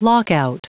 Prononciation du mot lockout en anglais (fichier audio)
Prononciation du mot : lockout